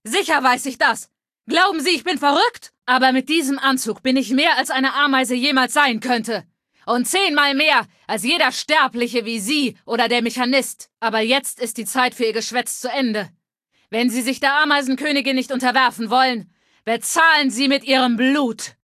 Femaleadult01default_ms02_ms02superheroexplain2_0003c8c6.ogg (OGG-Mediendatei, Dateigröße: 141 KB.
Fallout 3: Audiodialoge